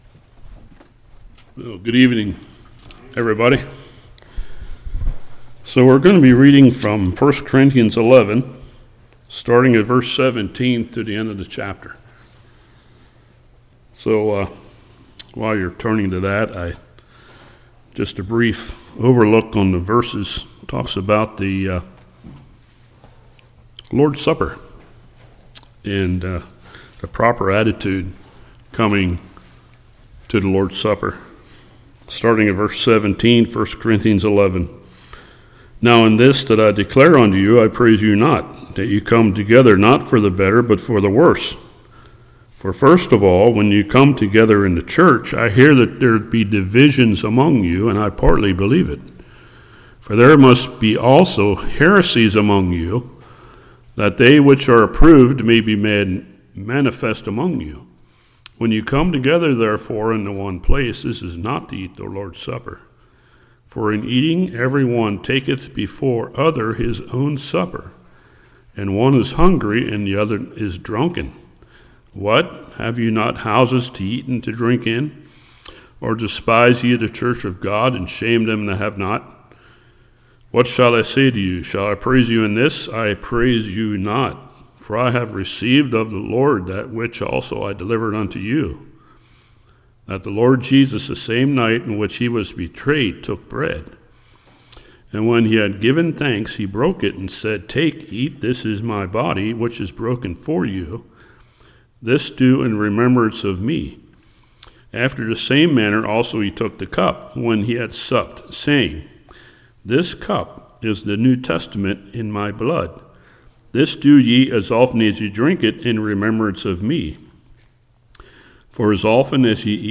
Service Type: Evening